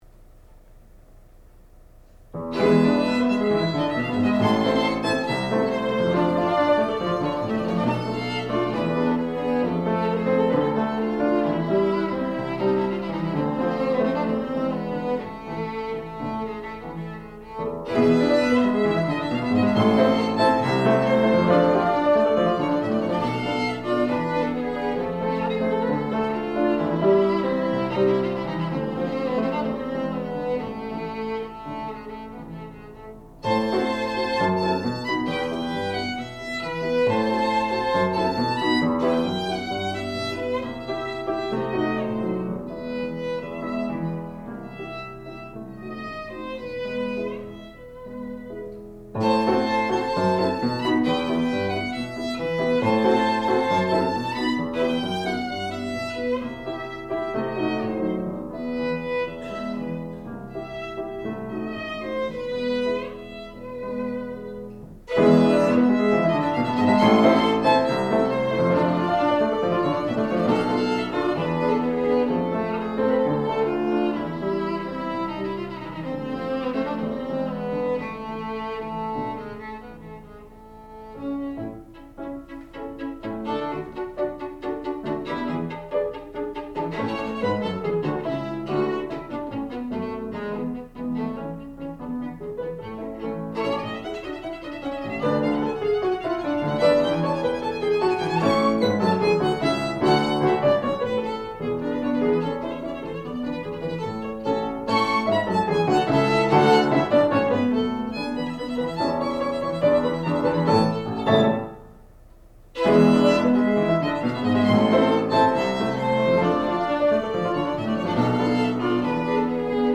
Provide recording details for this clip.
violin, Graduate recital